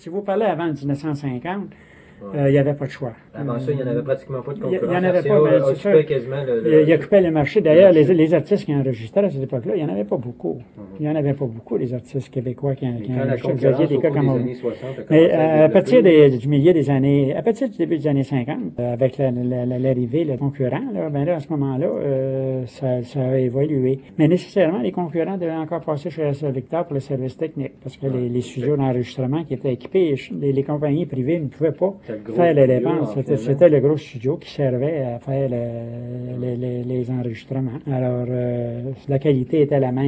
Extraits sonores des invités (Histoire des maisons de disques)